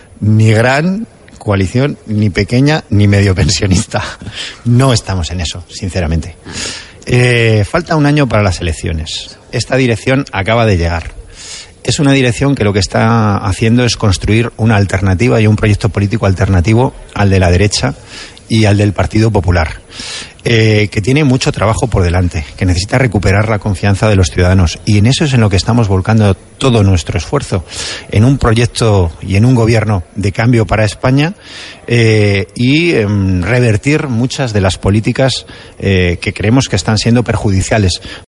Fragmento de la entrevista de Antonio Hernando en Las mañanas de RNE en la que niega la posibilidad de un pacto de gobierno con el PP 3/12/2014